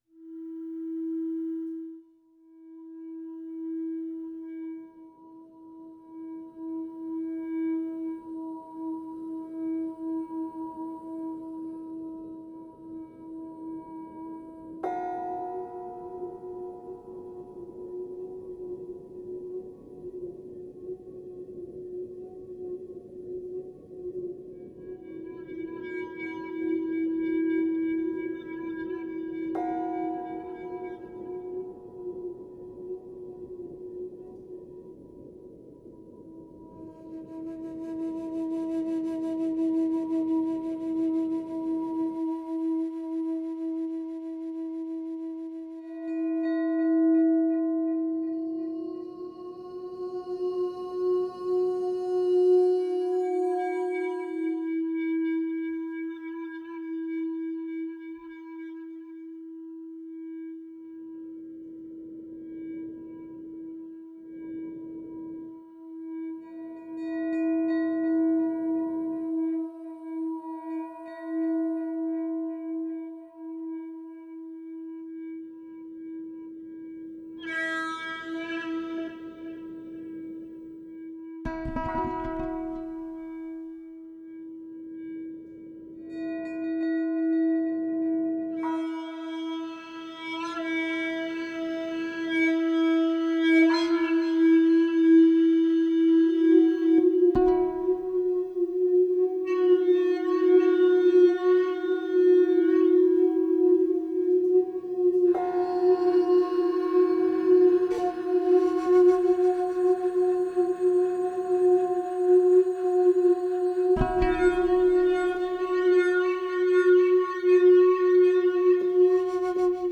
Cedrus - the Emeralds tree didgeridoo
Wood: Cedar, aromatic and strong length: 205 cm bell: 20 cm x 9,5 cm mouth: 28/30 mm Interior excavation with special processing and care, privileged sound and intonation TONE: C# (2) based on A=432 Hz equal tempered, carefully detected actual real frequency 68 Hz 1° Toot Bb (114 Hz~ 114,33 Hz) Sound: this didge sounds deep, nuanced, very harmonious